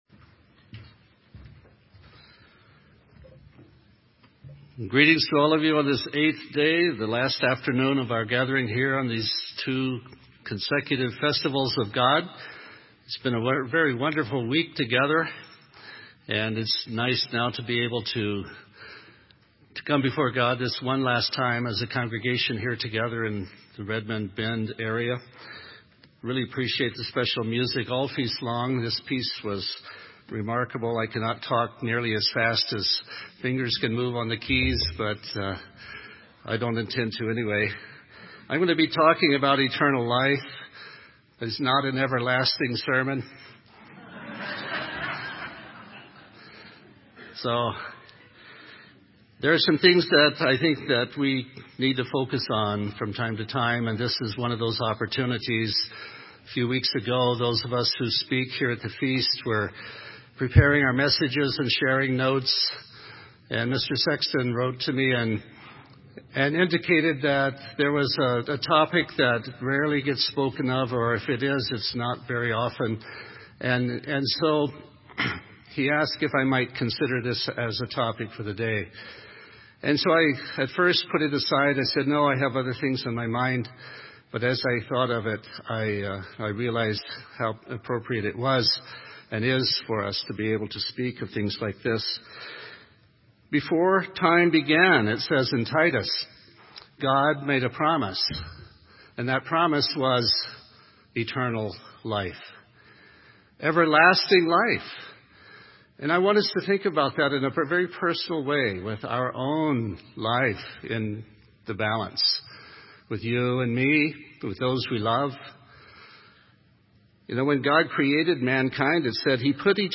This sermon was given at the Bend, Oregon 2015 Feast site.